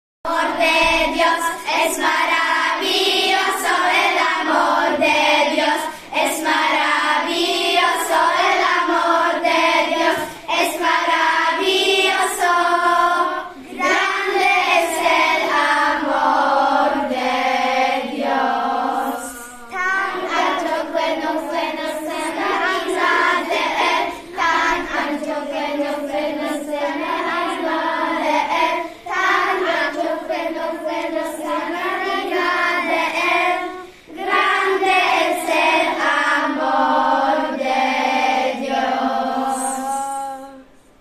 Wcześniej każda klasa przygotowała plakat o jednym z krajów z kontynentu, który jej został wyznaczony, a niektóre klasy nauczyły się śpiewać piosenki w języku w jakich mówi się na danym kontynencie czy też w kraju, o którym przygotowywały plakat.